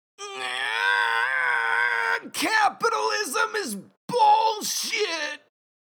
PLAY USSR ANTHEM